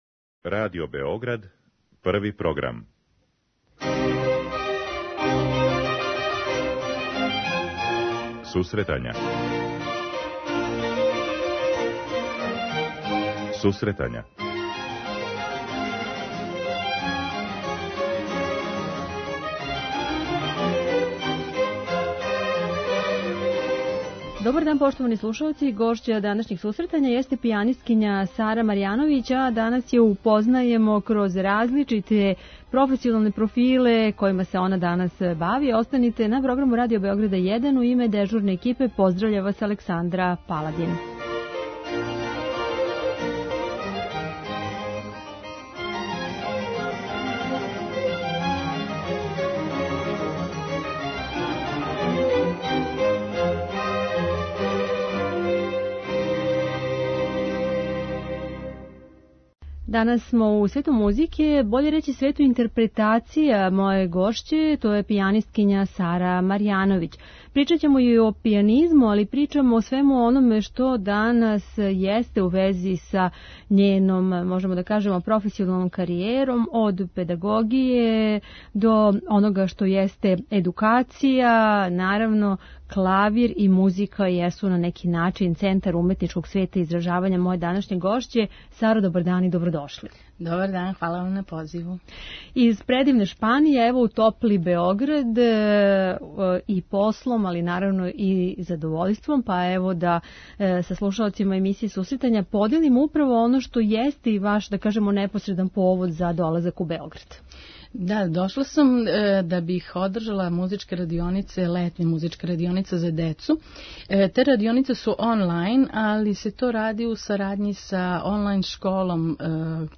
У данашњој емисији са овом уметницом разговараћемо о значају едукације у области музике за најмлађи узраст, као и радионицама које држи уметницима-извођачима са циљем да превазиђу страх од музичке сцене и трему. Такође, речју и музиком, представићемо и њему значајну професионалну каријеру у области пијанизма.